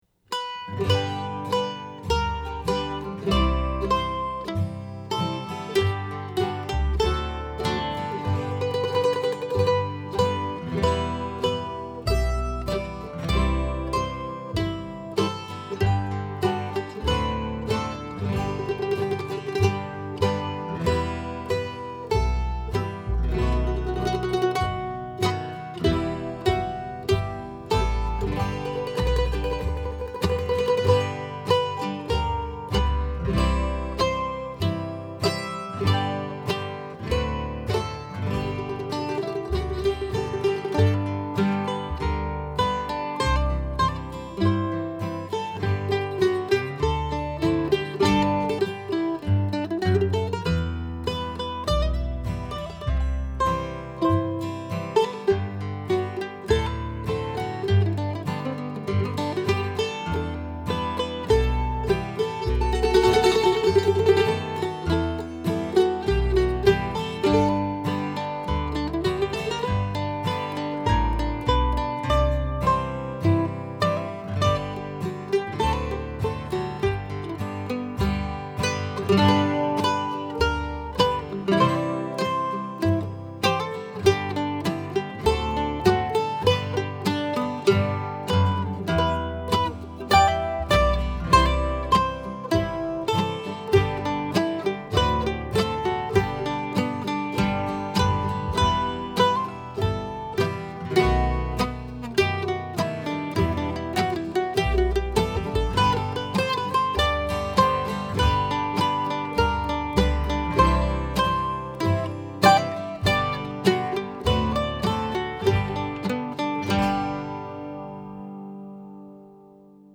DIGITAL SHEET MUSIC - MANDOLIN SOLO
• Christmas, Bluegrass,Mandolin Solo